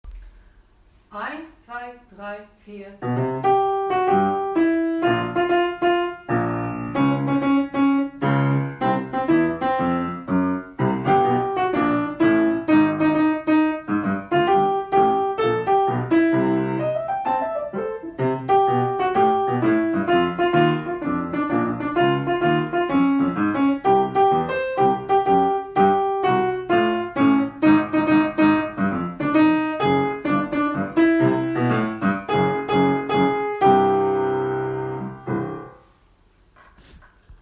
Alt 2- Baß ( untere Stimme).m4a